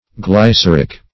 Search Result for " glyceric" : The Collaborative International Dictionary of English v.0.48: Glyceric \Gly*cer"ic\, a. (Chem.)